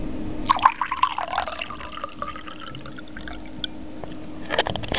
› Plätschern des Wassers [WAV | 108 KB] beim Wasserschöpfen als Reaktion auf das Orange einer Gießkanne, die die Drachen zum Gießen der Bäume mit auf die Bühne bringen.
SLH_Brunnenplaetchern.wav